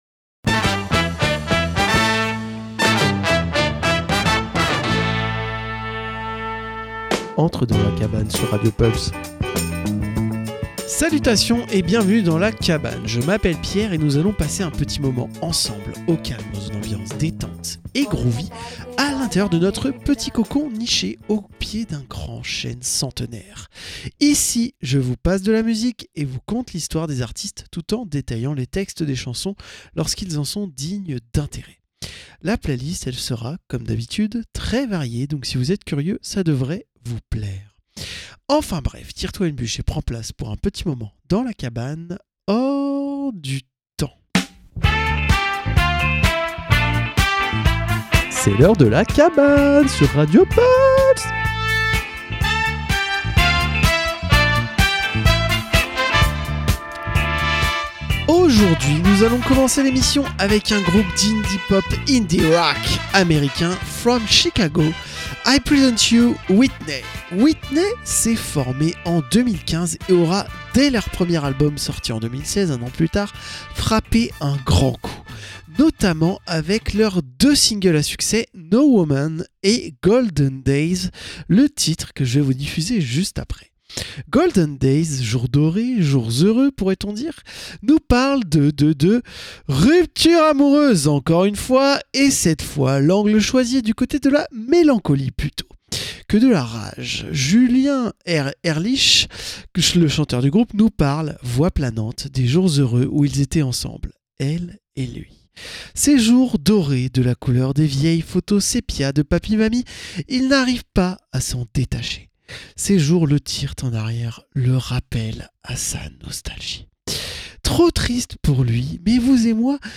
La cabane, détente dans une ambiance chill/groovy avec une playlist éclectique allant du rock à la funk en passant par la pop, le rap ou l'électro. Au programme : écoute et découverte ou redécouverte d'artistes et explication des paroles de grands classiques de la musique !